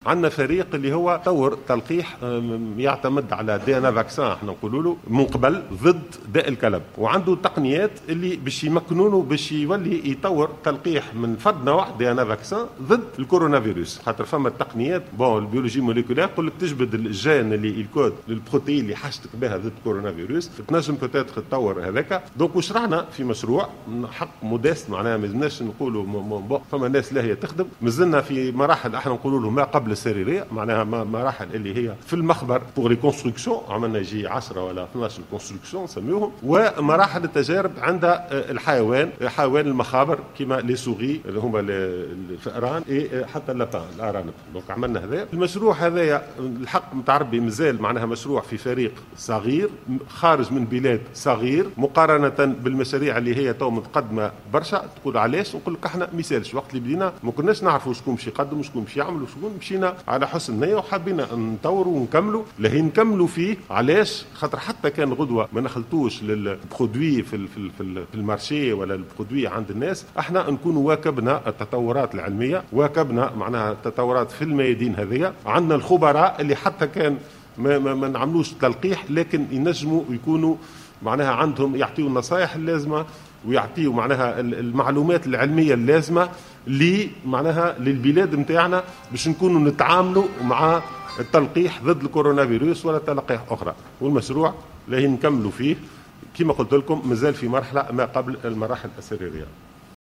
وأوضح في ندوة صحفية أن فريق خبراء متخصّص بمعهد باستور يعمل على تطوير لقاح ضد كورونا يعتمد على تقنية الحمض النووي (DNA Vaccin) وبنفس المقاربة التي تم اعتمادها في تطوير لقاح ضد داء الكلب.